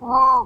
sounds_penguin_01.ogg